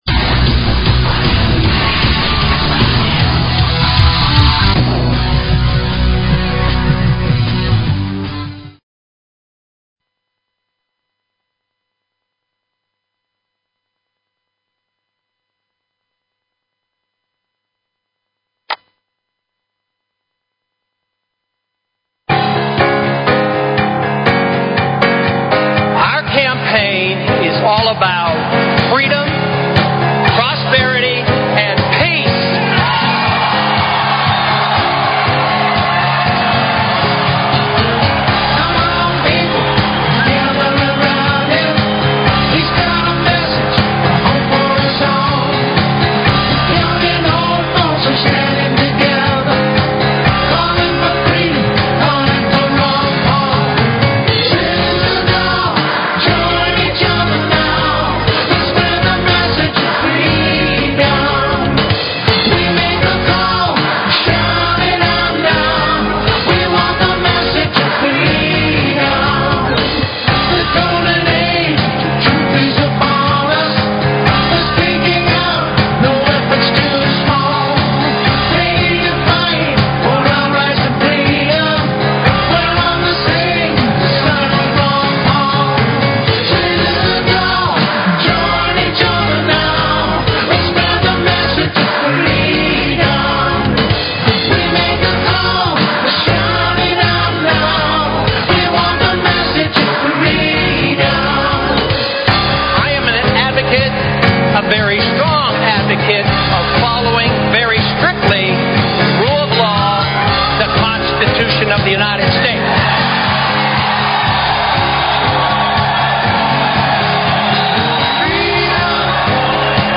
Talk Show Episode, Audio Podcast, The_Freedom_Message and Courtesy of BBS Radio on , show guests , about , categorized as
It's a live internet radio call in show for and about the Ron Paul Revolution. It serves as a media outlet for campaign announcements and news, a vehicle for the organization of Ron Paul support, a medium for the dissemination of ideas and tactics and a tool for the mobilization of large numbers of supporters in the movement to elect Ron Paul as our next President.